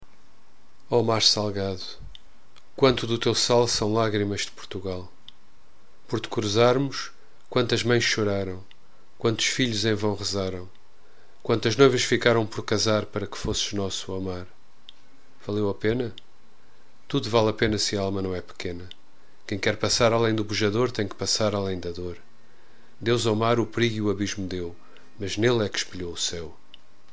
Voz 1
Index of poems with explanation and readings